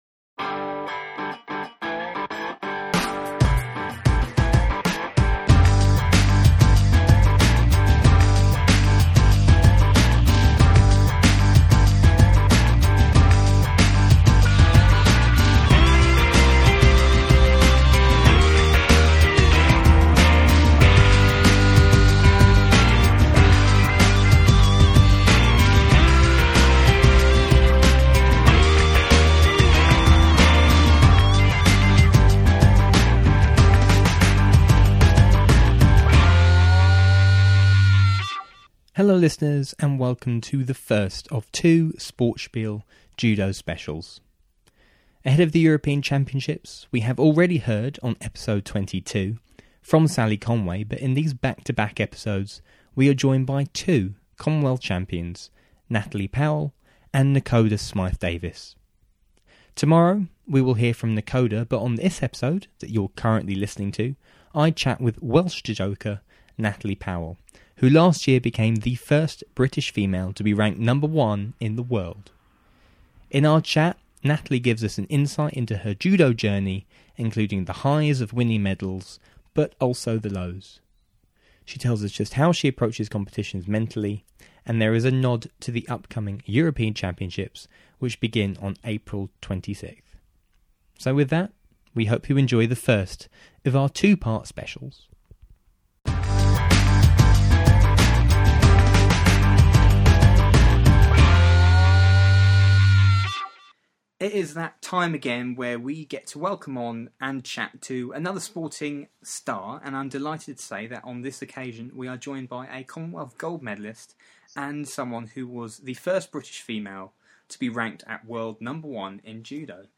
In her one-on-one interview on the podcast Natalie takes us through her journey in judo as well as becoming the first British female to be ranked world number one.